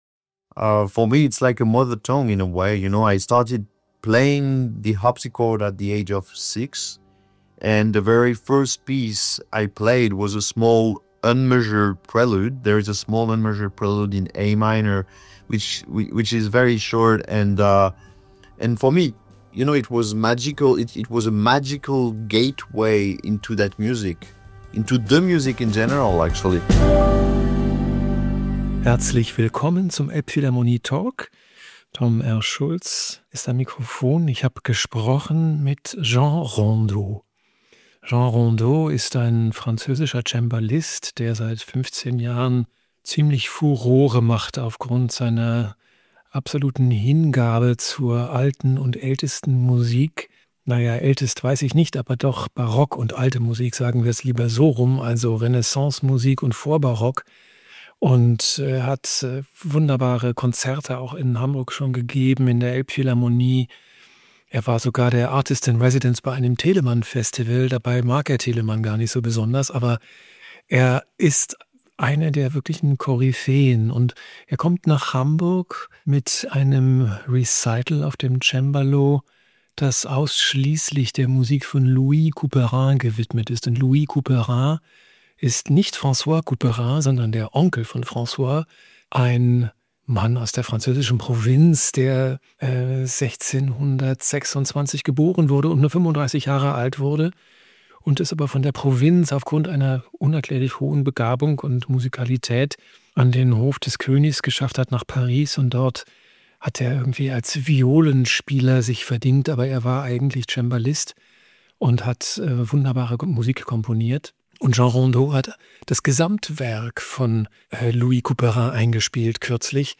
Letzte Episode Elbphilharmonie Talk mit Jean Rondeau 26. Januar 2026 download Beschreibung Teilen Abonnieren Im Interview spricht der Cembalist Jean Rondeau über sein neustes Großprojekt.
elbphilharmonie-talk-mit-jean-rondeau-mmp.mp3